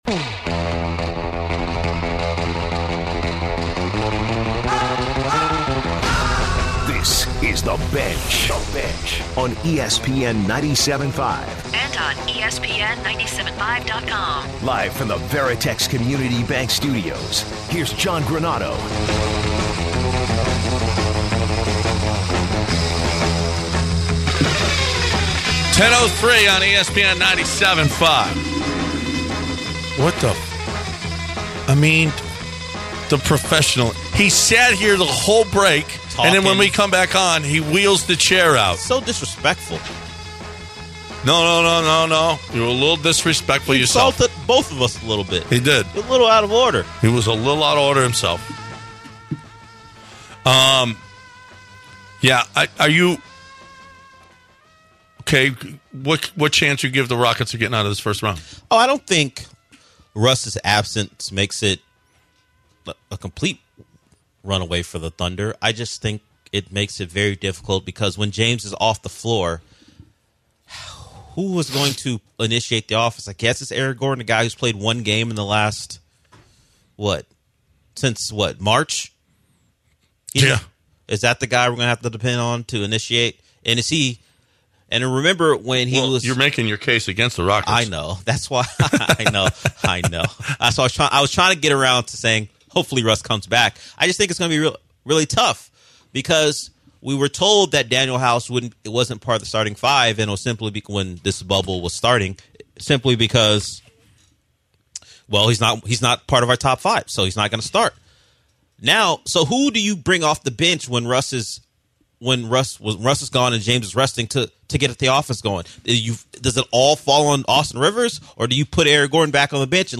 Lastly the guys take calls from listeners.